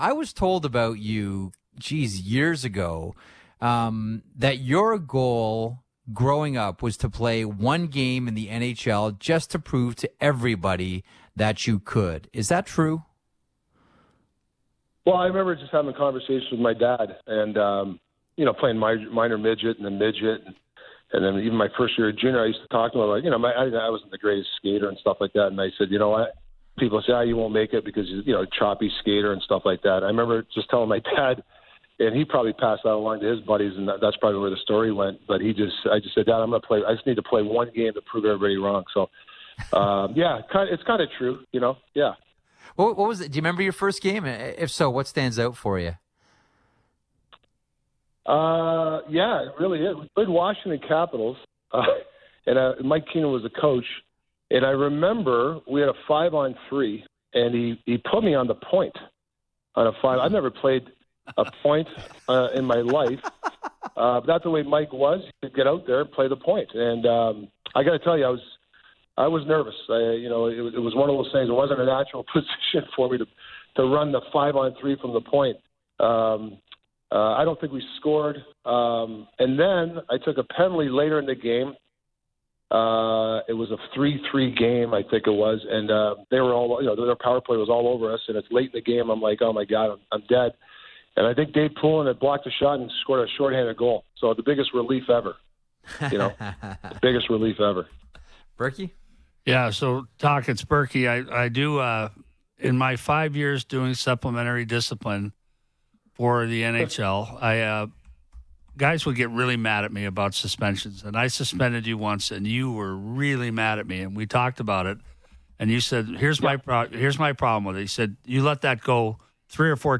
Sportsnet Hockey Central have spent time over the last few weeks speaking with many of our head coaches during the paused NHL season.